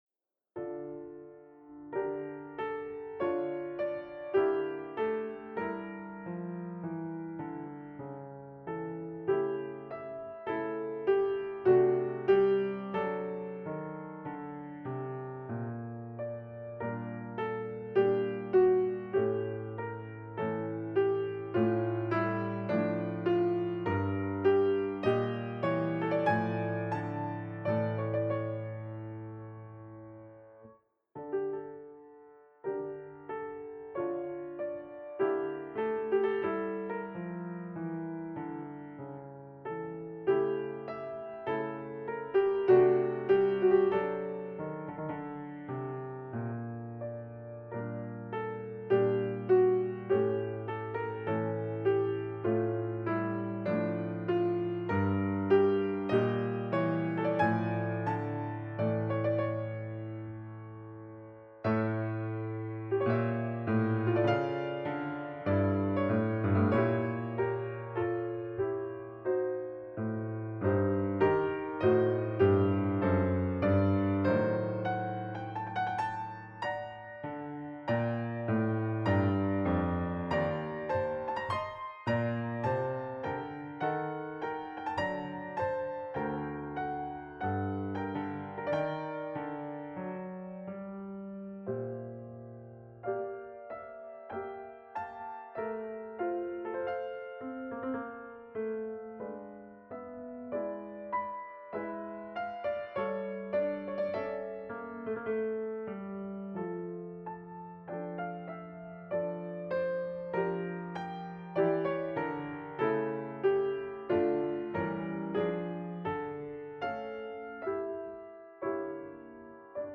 Enregistrement d'un récital de piano
Le lendemain, ce sont ces oeuvres qu'elle a enregistré à l'auditorium, sur le piano à queue.
Pour la prise de son, je me suis servi de 4 micros statiques : un couple de Rode NT5 en proximité (près des cordes), et 2 AKG 451 en prise d'ambiance.
Pour des raisons évidentes de taille de fichier et de temps de téléchargement, les fichiers Audio ont été compressés au format MP3 avec un taux de 160 Kbps, ce qui garantit une qualité d'écoute correcte.
piano